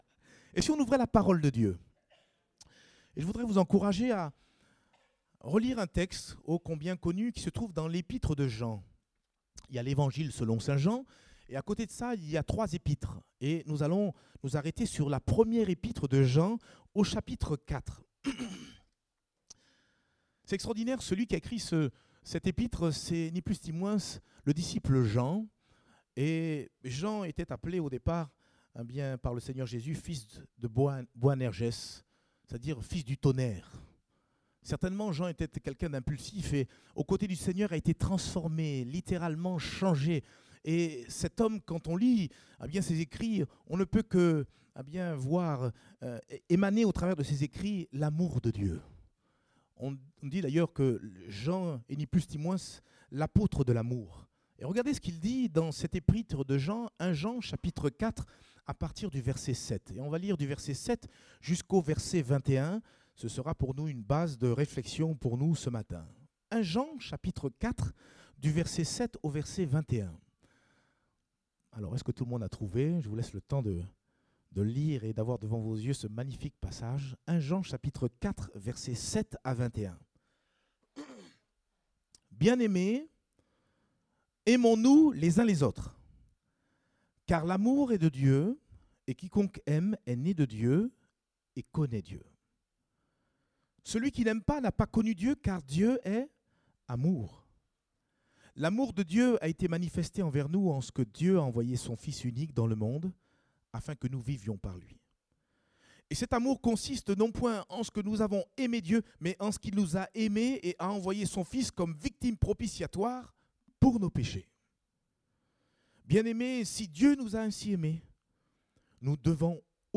Date : 24 juin 2018 (Culte Dominical)